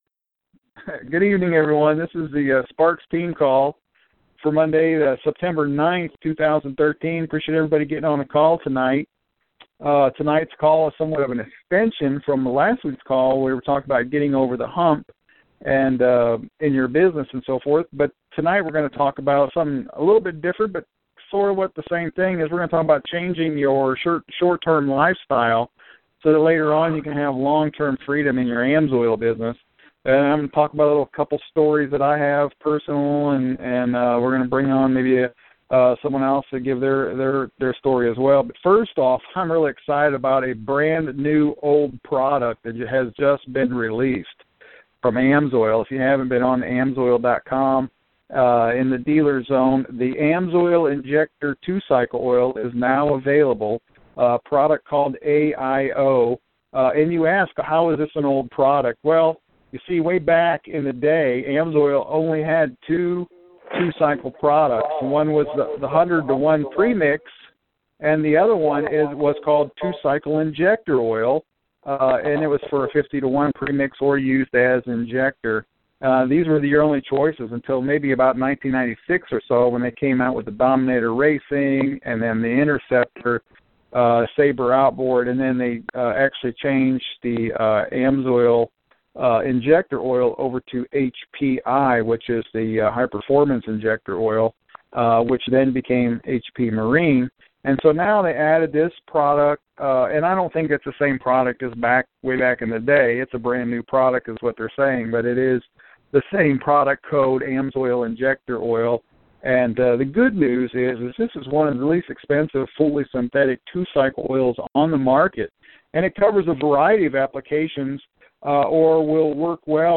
AMSOIL Dealer training call talking about reducing personal and business expenses